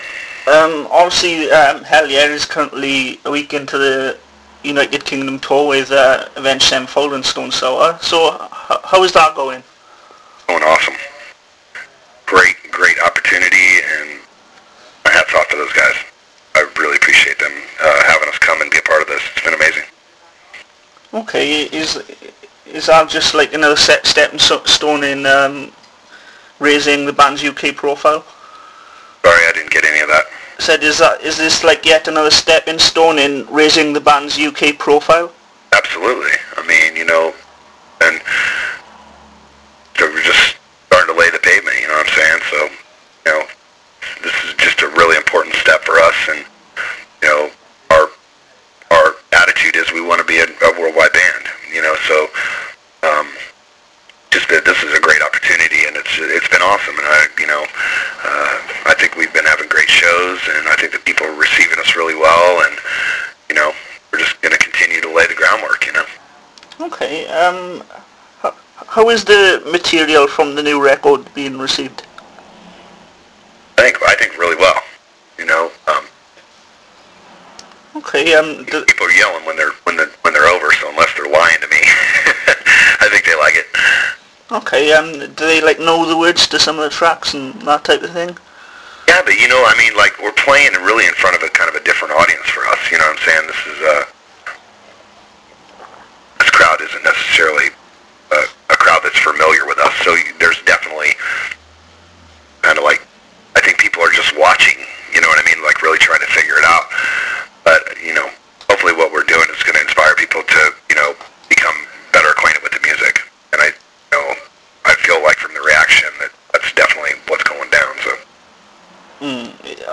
Life the �Cowboy Way� - Hellyeah vocalist Chad Gray talks about second studio album Stampede
You can now listen to the chat in audio format.